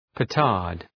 Προφορά
{pı’tɑ:rd}